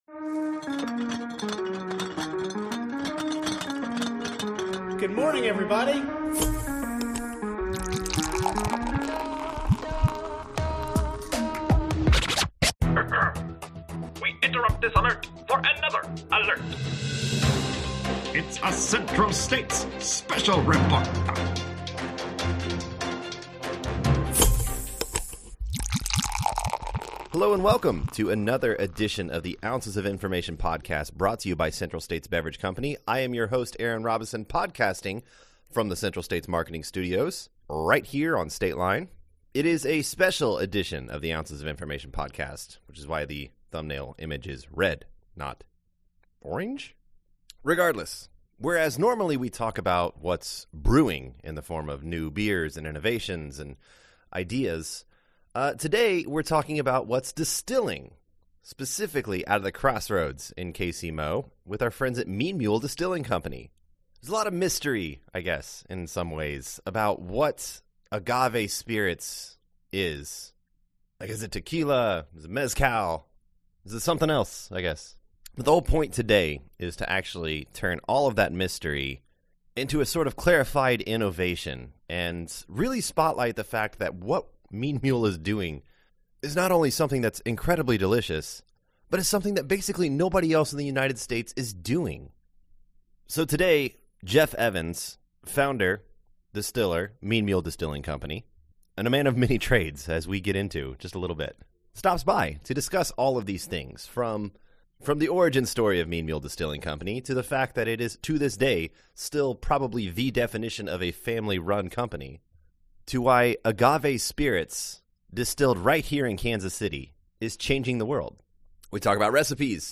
in studio to discuss the unique process and products coming out of the KC Crossroads distillery.